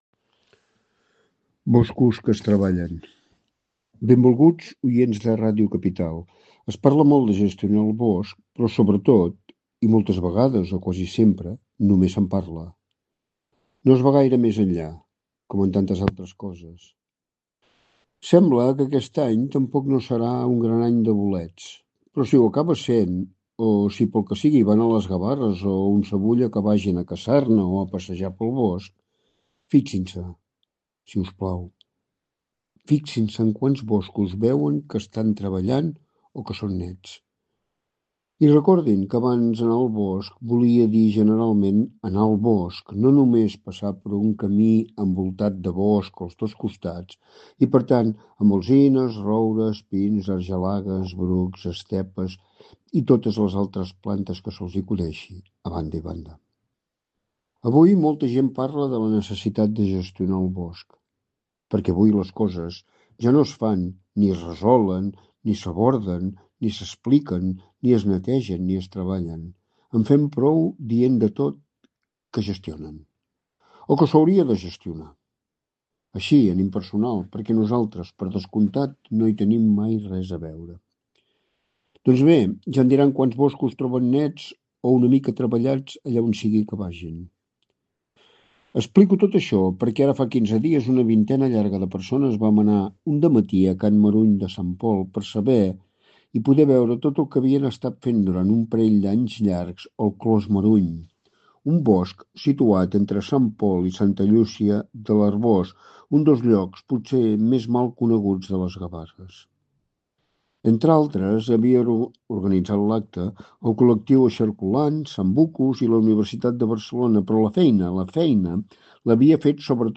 Entrevista a Shinova - 15/12/2016 • Ràdio Capital de l'Empordà